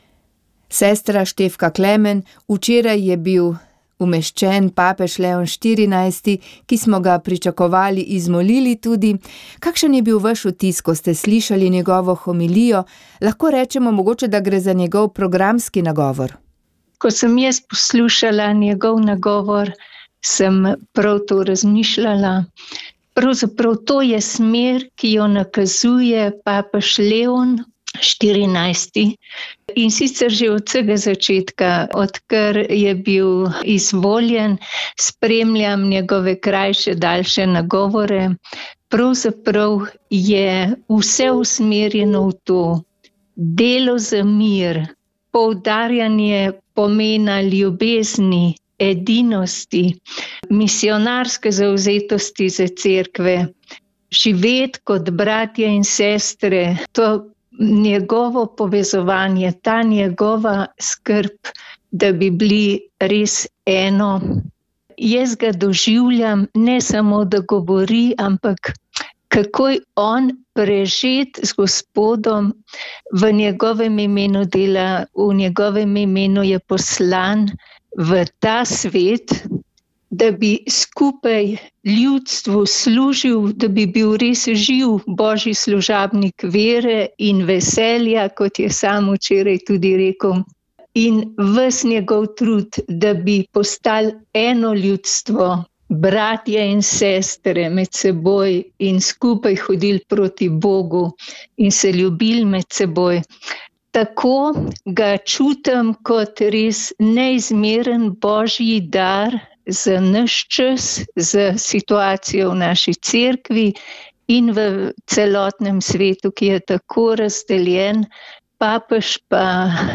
Sveta maša